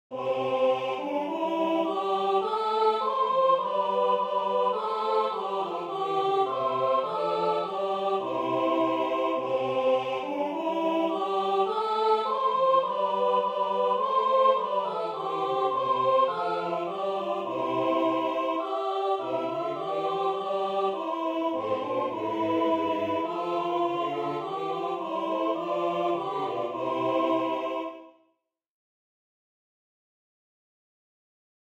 This arrangement is to be sung with the congregation. The organ and congregation use the hymnal. The piano can play the choir parts if desired.
Voicing/Instrumentation: SAB We also have other 36 arrangements of " He is Risen ".
Choir with Congregation together in certain spots